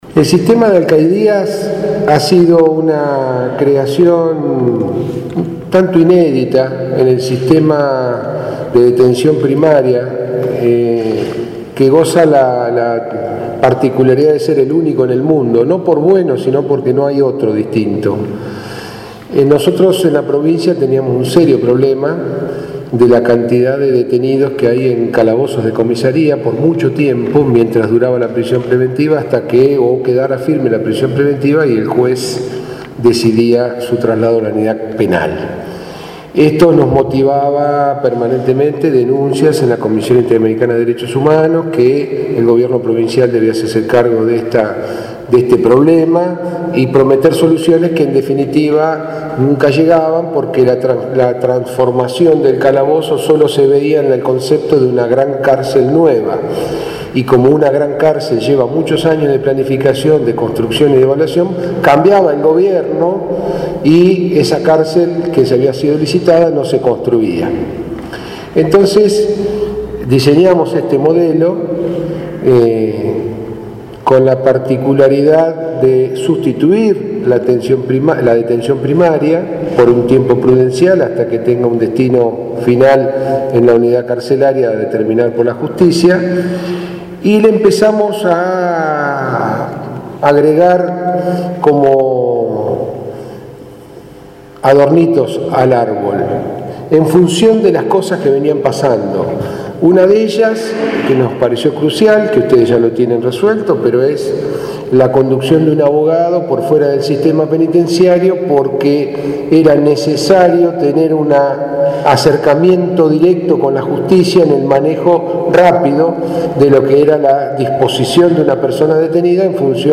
Del acto, desarrollado en el salón Blanco en la sede de Gobierno local, participaron, además, el ministro de Obras y Servicios Públicos, Julio Schneider; el secretario de Gobierno de Rosario, Fernando Asegurado, y los concejales locales, Diego Giuliano, y Miguel Cappiello, entre otros.